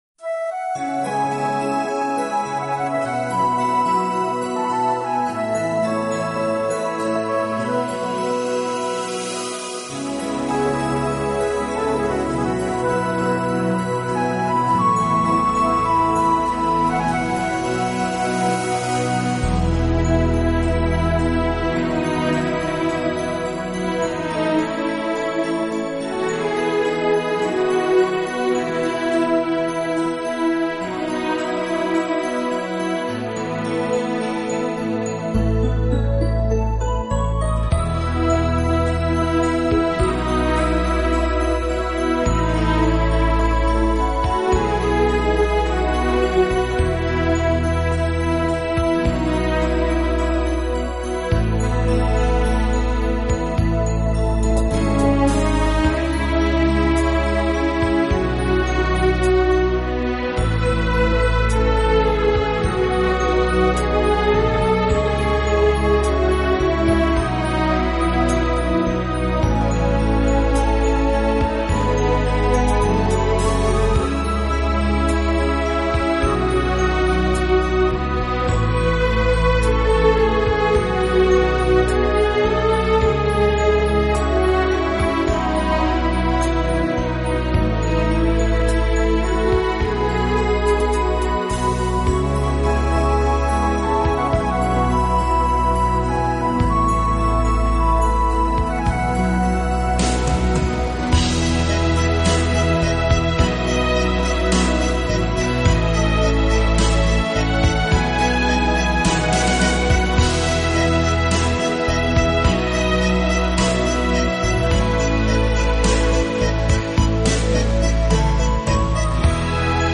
Genre: Instrumental